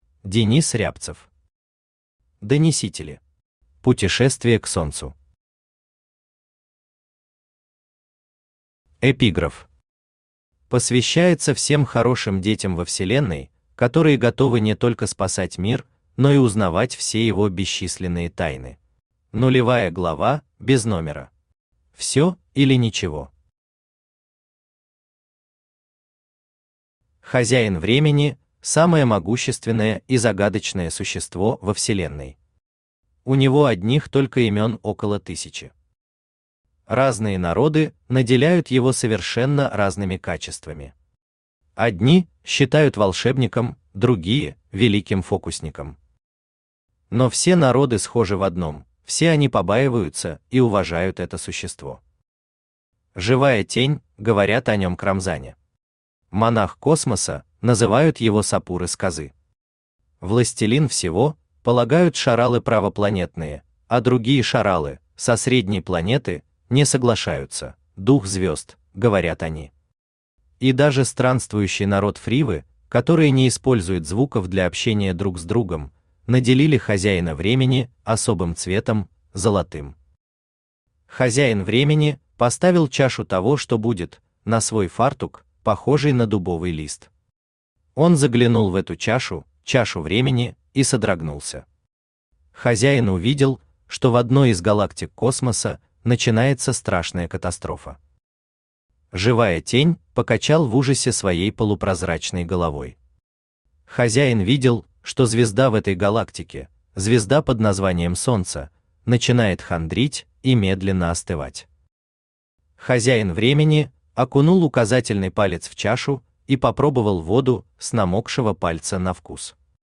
Аудиокнига Донесители. Путешествие к Солнцу | Библиотека аудиокниг
Путешествие к Солнцу Автор Денис Евгеньевич Рябцев Читает аудиокнигу Авточтец ЛитРес.